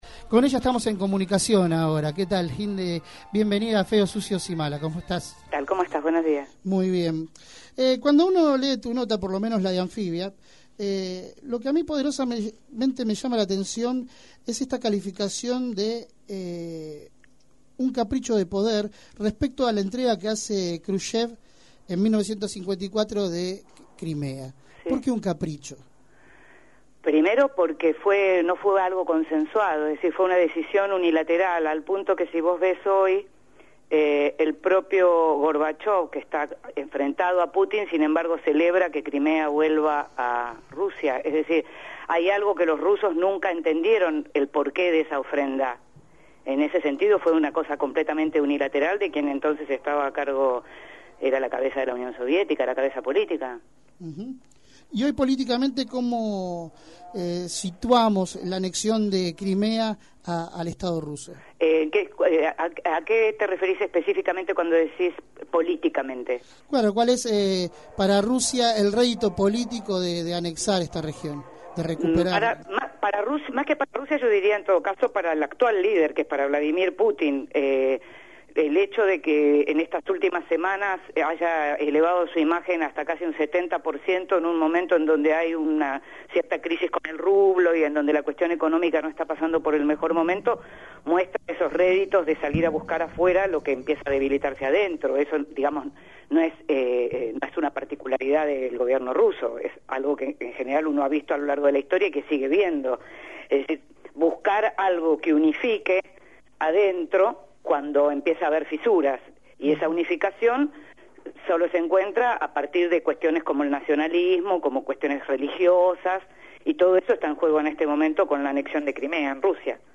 En la entrevista que brindó para Feos, Sucios y Malas